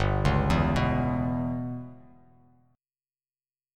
G6b5 Chord
Listen to G6b5 strummed